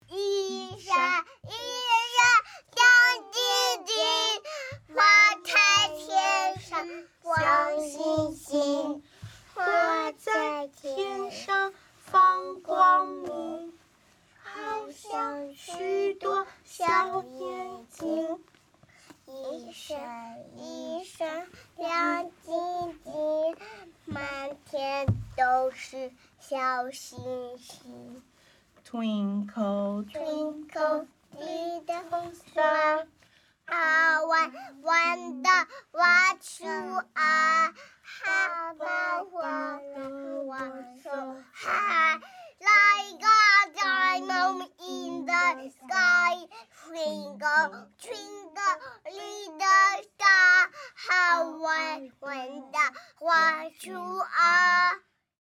Nursery Rhymes By Hiba Nursery EY1B – Twinkle Twinkle